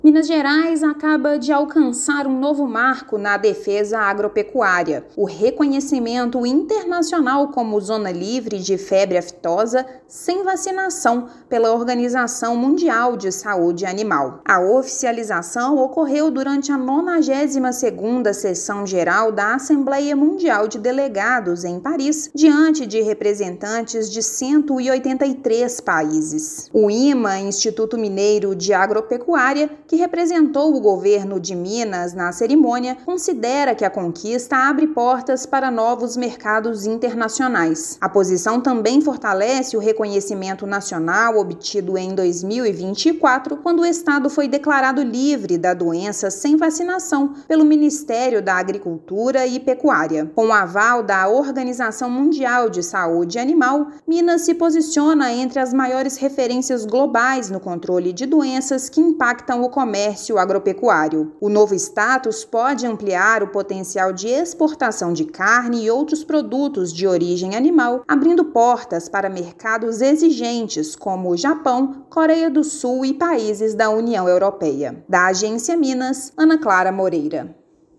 Com a presença de representantes do IMA, status sanitário foi oficializado durante a 92ª Sessão Geral da Organização Mundial da Saúde Animal (OMSA). Ouça matéria de rádio.